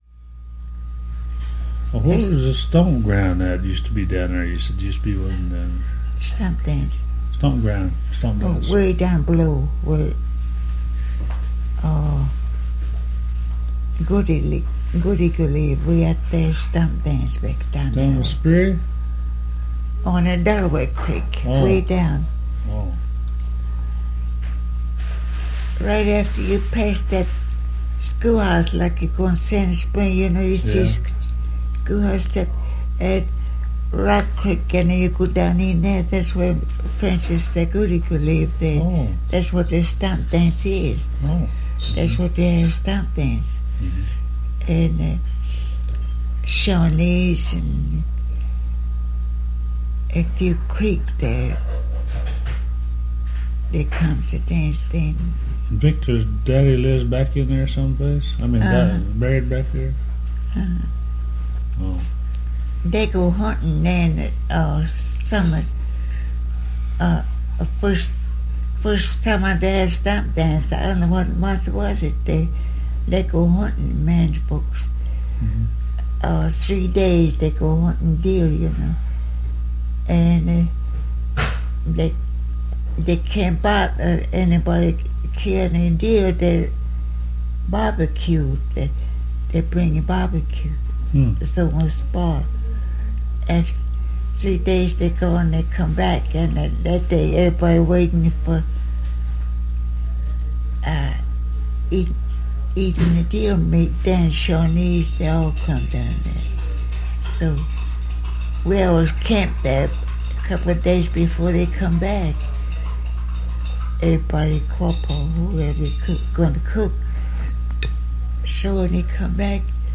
Quapaw Tribe Oral History